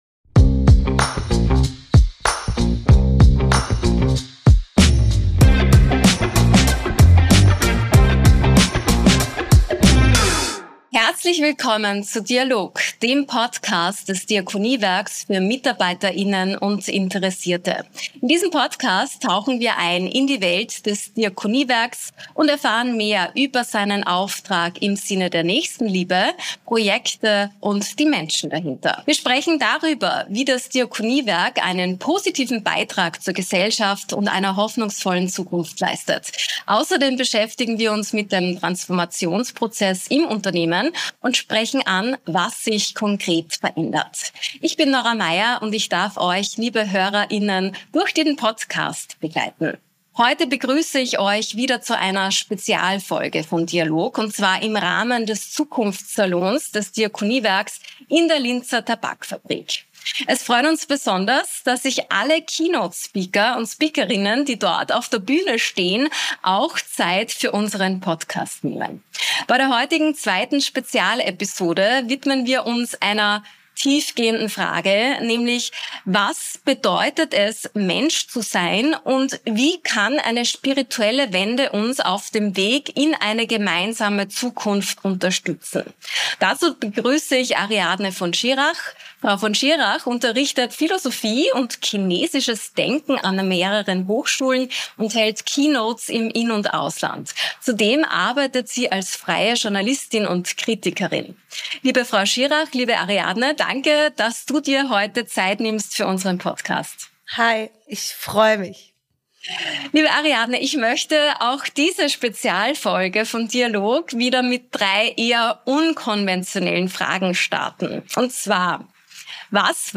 In dieser besonderen Episode von "DIA-LOG" ist Ariadne von Schirach zu Gast.